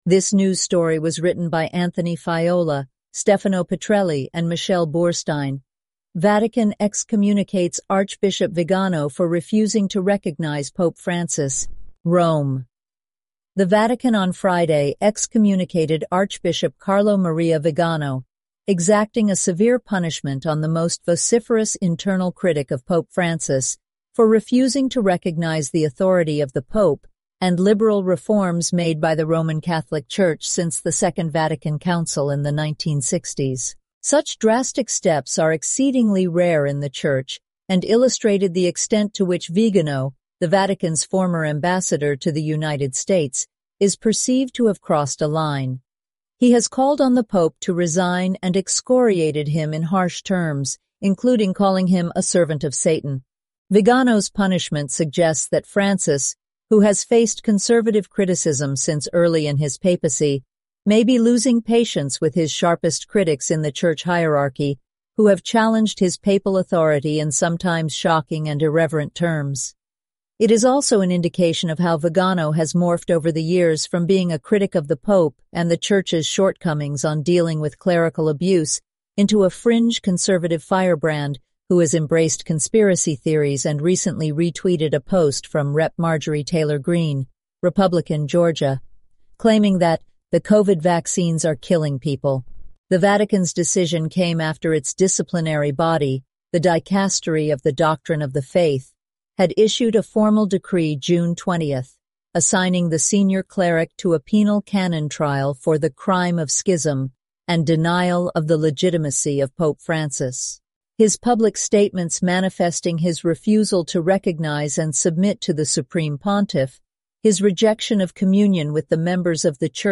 eleven-labs_en-US_Maya_standard_audio.mp3